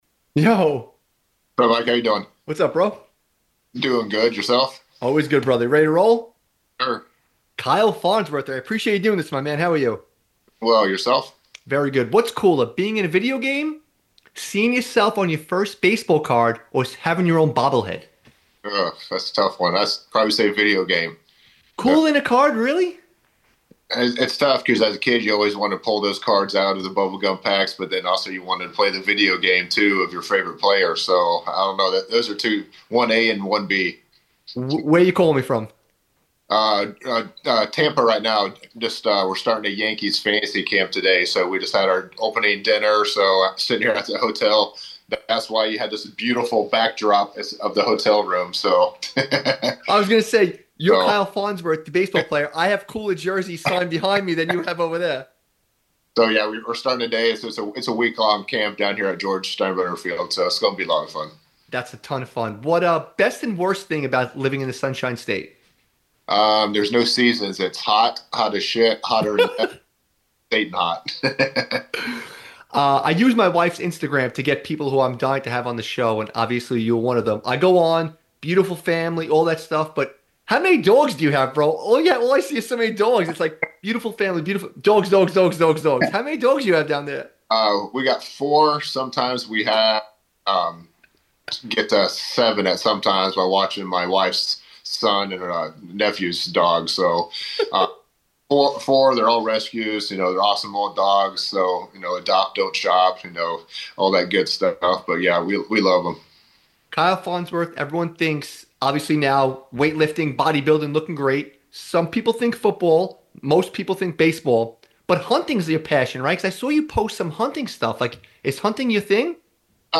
Join us today for an incredible conversation with MLB veteran, former New York Yankee, and professional bodybuilder Kyle Farnsworth. In this episode, Kyle opens up about his beautiful family, his love for dogs, and his passion for hunting.